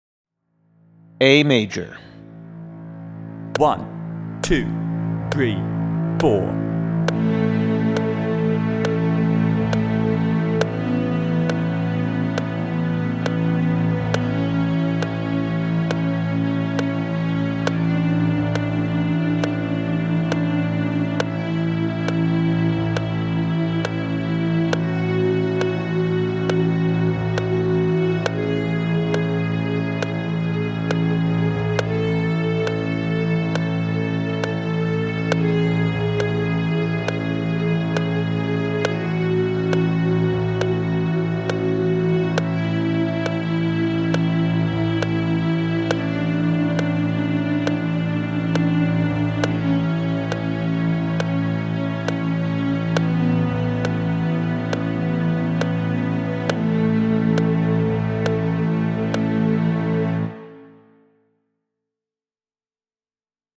The scales are played in whole notes, with quarter note at about 70 bpm.
A Major
04_A_Major_Scale_with_Drone.mp3